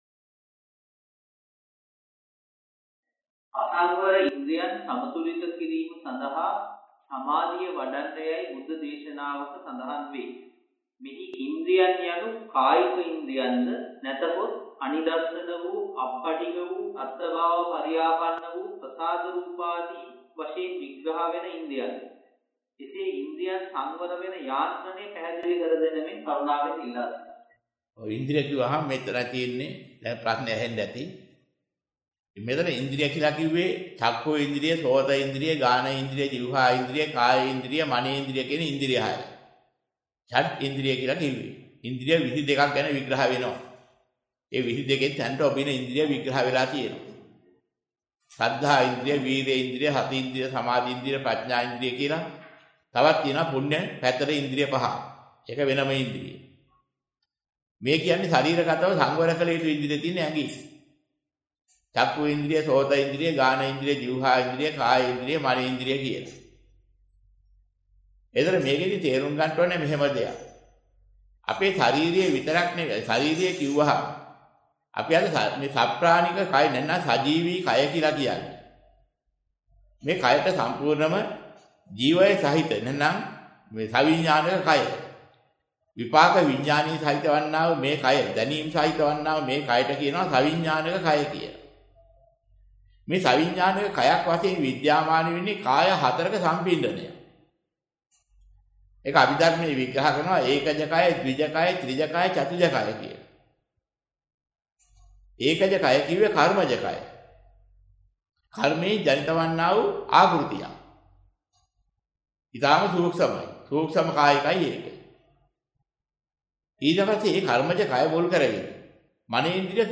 සංවර කලයුතු "ඉන්ද්‍රිය" පැහැදිලි කරදෙන්න ඔබගේ බ්‍රව්සරය පැරණියි. වෙනත් බ්‍රව්සරයක් භාවිතා කරන්නැයි යෝජනා කර සිටිමු 06:31 10 fast_rewind 10 fast_forward share බෙදාගන්න මෙම දේශනය පසුව සවන් දීමට අවැසි නම් මෙතැනින් බාගත කරන්න  (4 MB)